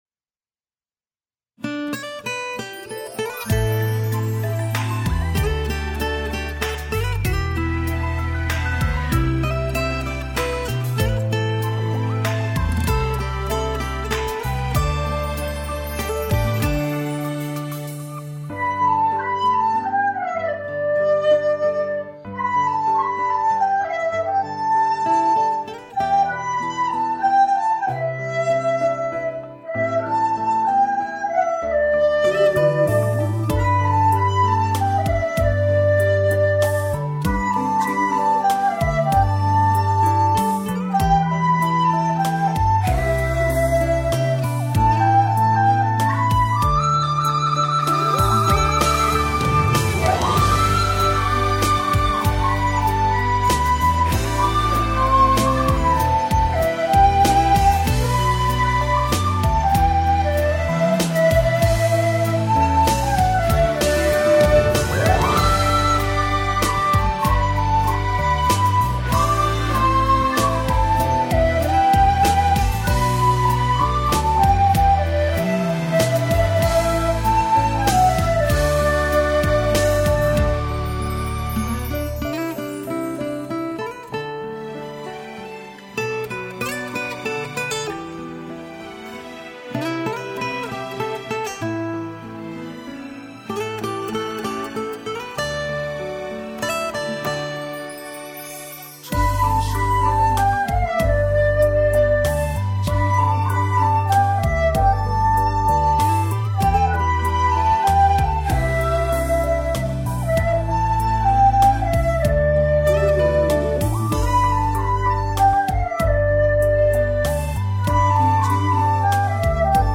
抽空赶吹个笛子版的，凑个热闹。
笛声清脆，旋律悠扬，太好听了2
清脆优美的笛声，好好听哦！